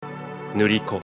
Nu-Ri-Ko (equally pronounced syllables, the nu as in the North American way of saying "new" and the ko as in the start of "confidence")- not Nu-Ree-Koh as the English dub seems to label him (giving too much emphasis to the middle syllable).
nuriko_pronunc.mp3